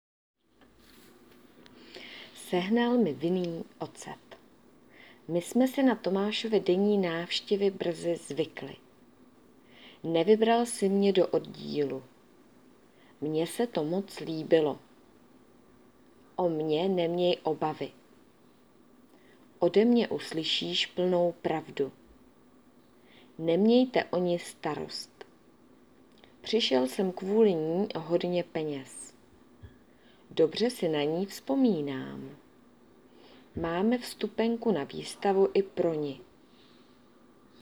diktát